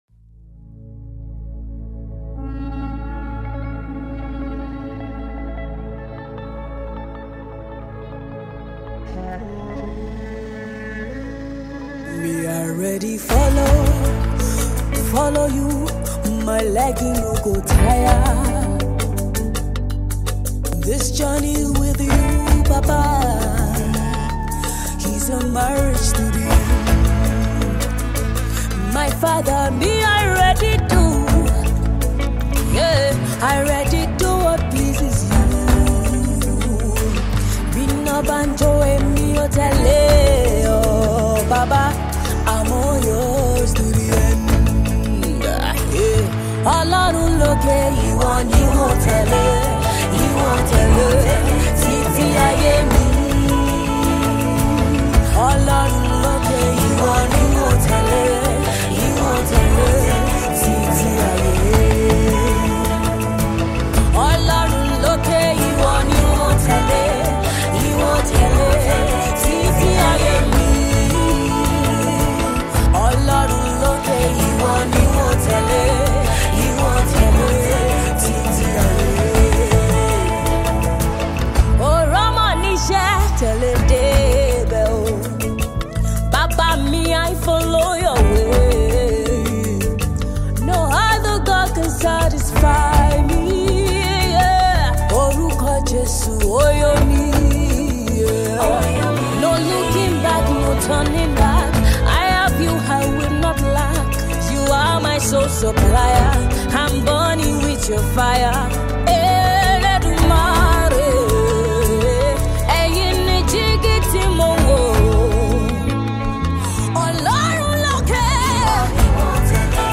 Gospel music